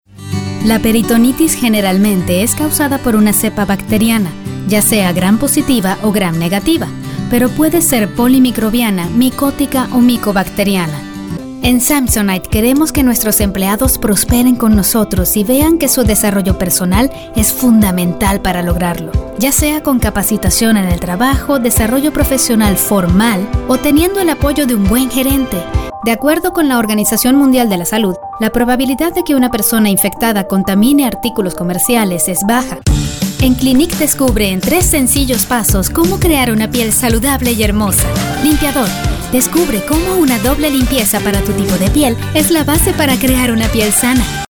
E-Learning
confident, informative, Matter of Fact, professional, spanish-showcase